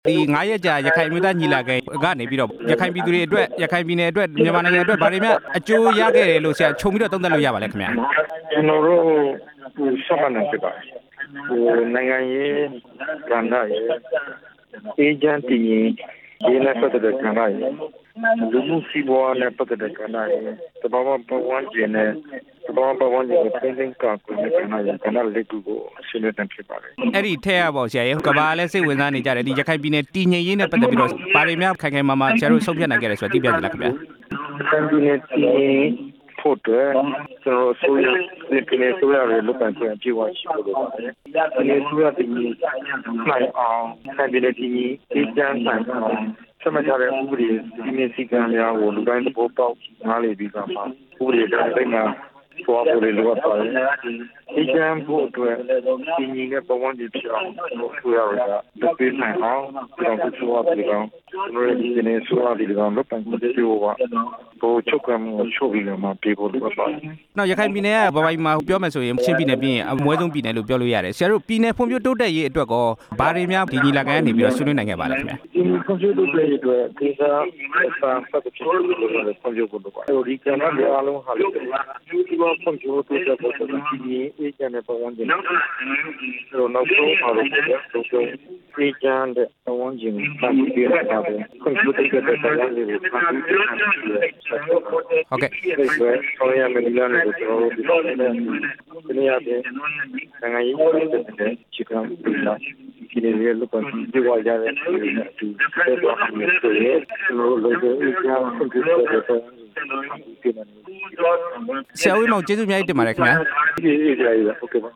ဒေါက်တာအေးမောင်နဲ့ မေးမြန်းချက်